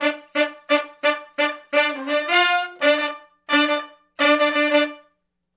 065 Funk Riff (D) uni.wav